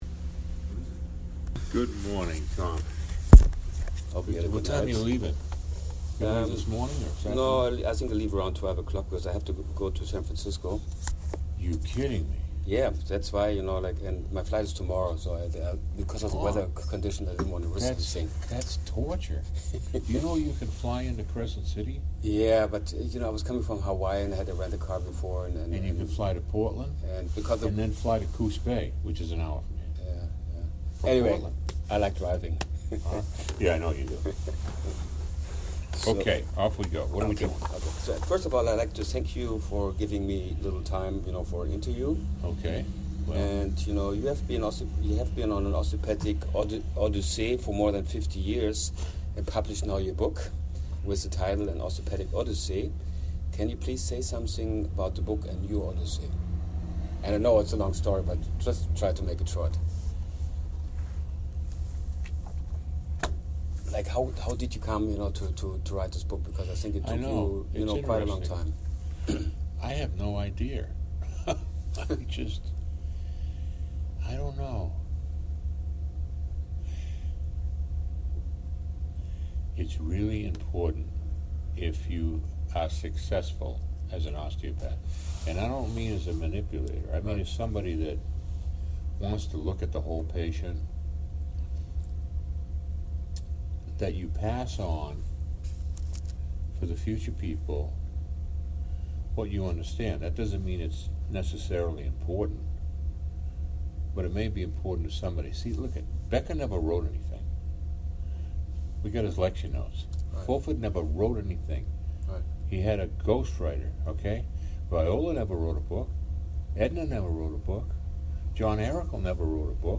Interview in englisch